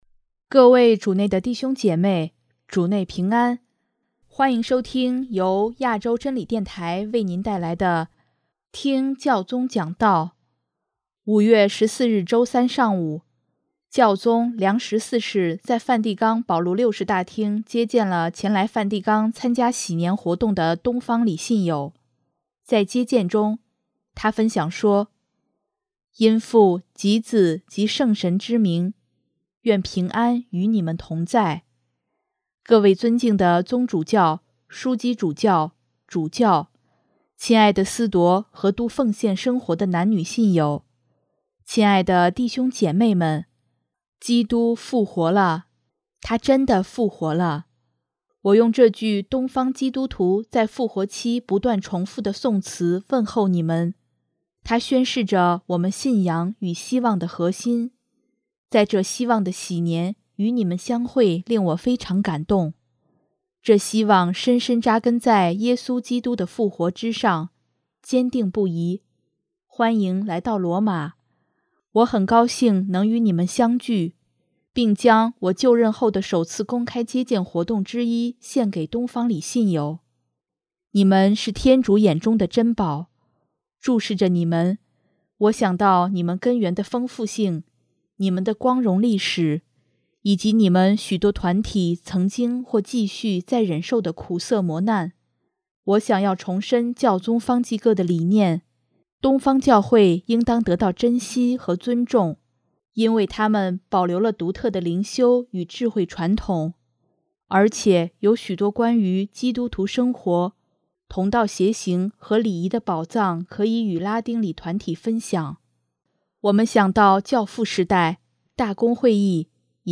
【听教宗讲道】|保护东方礼的重要性远超人们想象
5月14日周三上午，教宗良十四世在梵蒂冈保禄六世大厅接见了前来梵蒂冈参加禧年活动的东方礼信友，在接见中，他分享说：